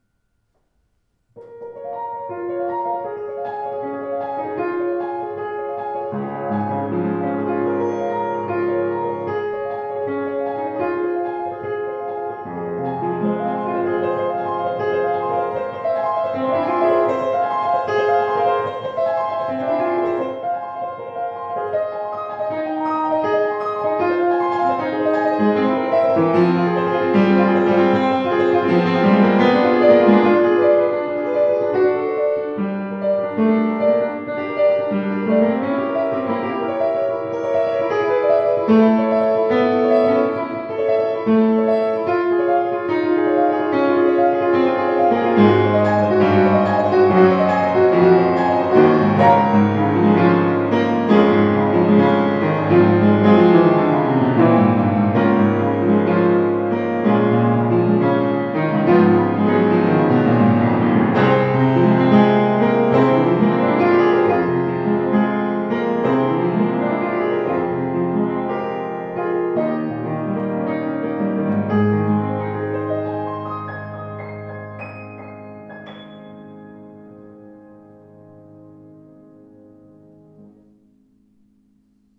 Sauter 115 gebraucht schwarz
Gestaltungsfähiger Klang, angenehme, flüssige Spielart.